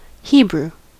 Ääntäminen
US Tuntematon aksentti: IPA : /ˈhiːbɹuː/